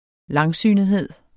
Udtale [ ˈlɑŋˌsyˀnəðˌheðˀ ]